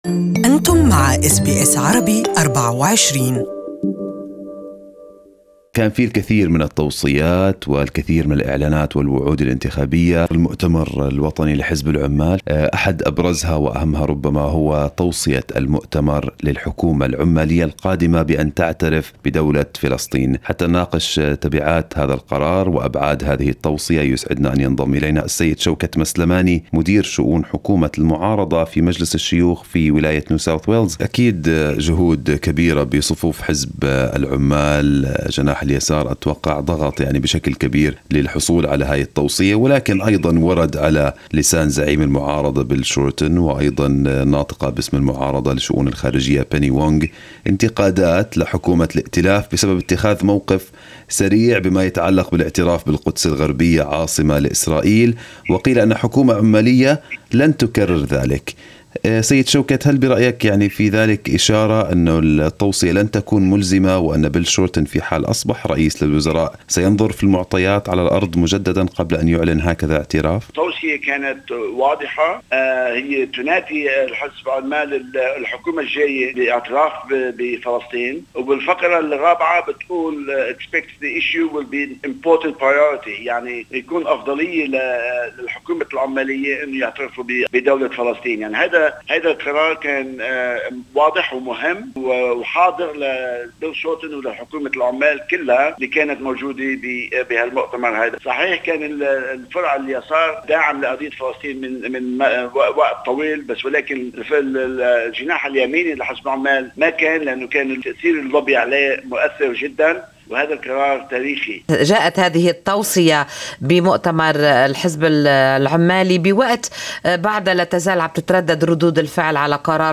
Opposition whip in NSW parliament, Shawkat Maslamani spoke to SBS Arabic24 about the nature of the resolution and the expected steps to be taken by a future Labor Government in this regard.